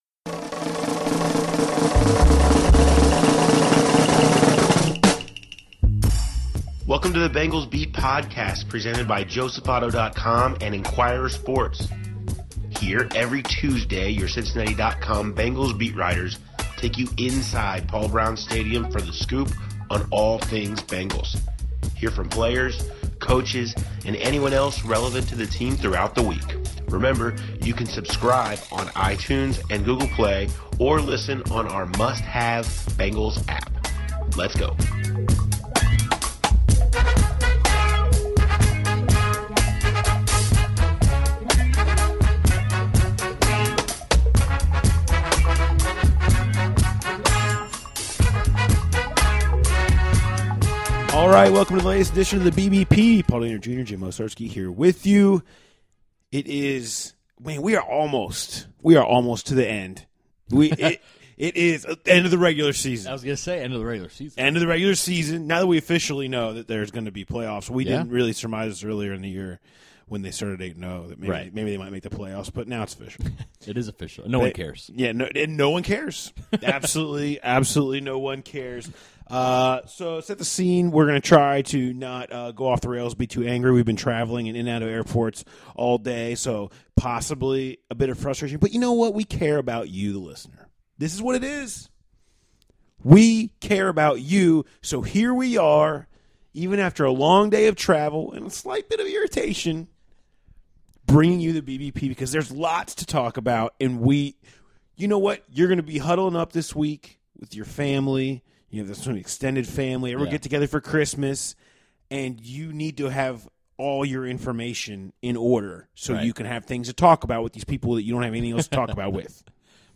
Plus, hear from running back Jeremy Hill and linebacker Vontaze Burfict on their evolving situations.